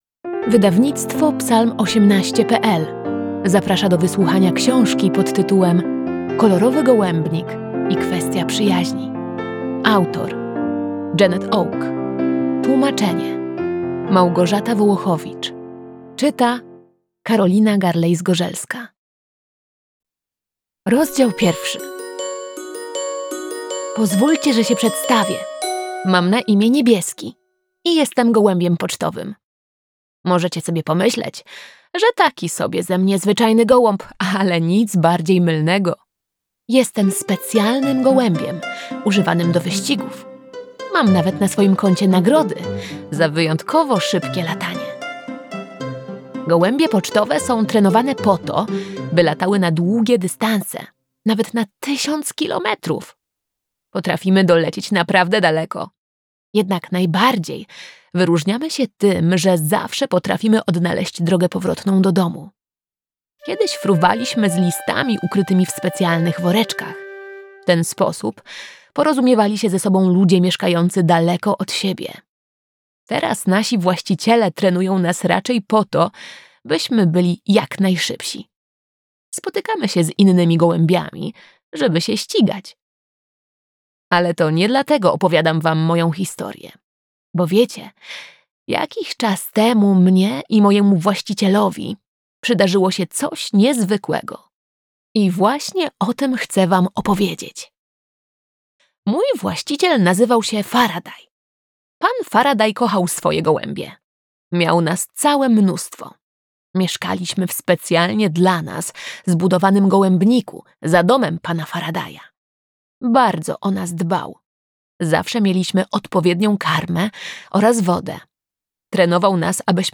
Kolorowy gołębnik i kwestia przyjaźni - Audiobook
01_Rozdzial_1_-Kolorowy_golebnik-Janette_Oke-audiobook.mp3